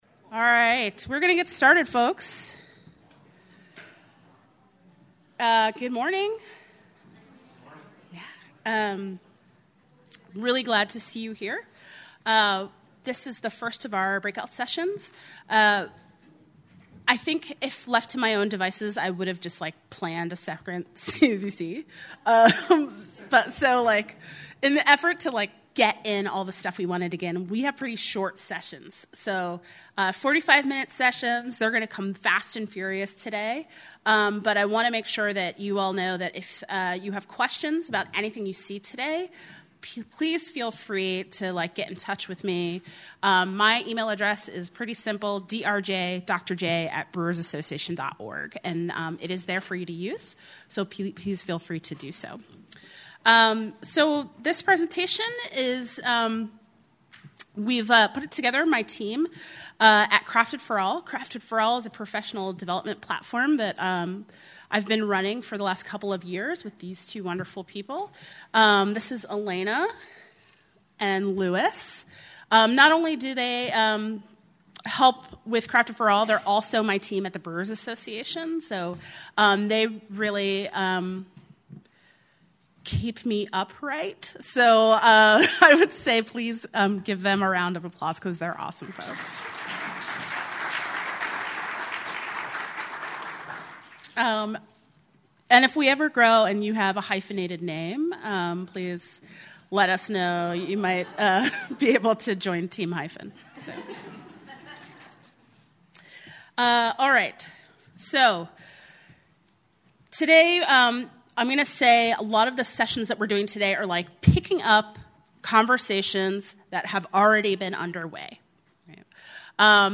Seminar
Craft Brewers Conference 2022 Minneapolis, Minnesota